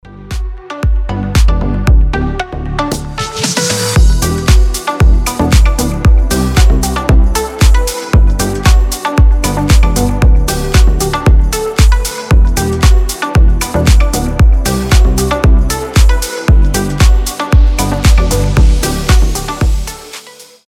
• Качество: 320, Stereo
красивые
deep house
мелодичные